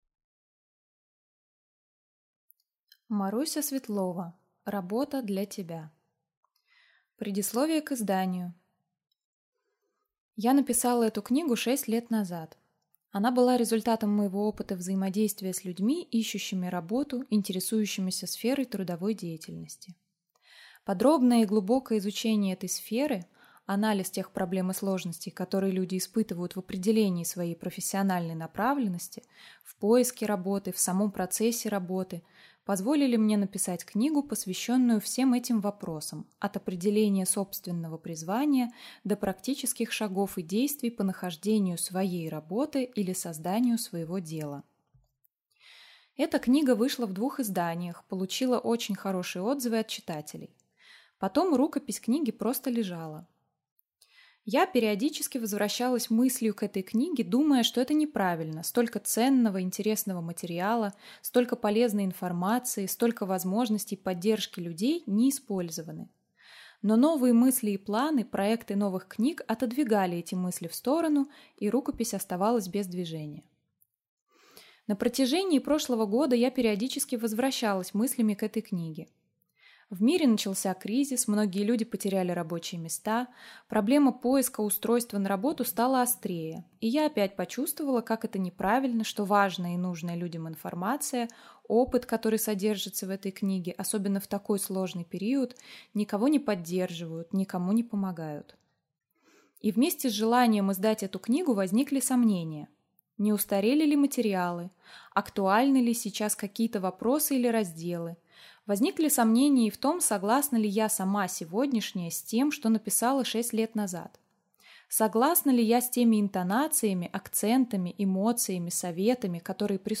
Аудиокнига Работа для тебя | Библиотека аудиокниг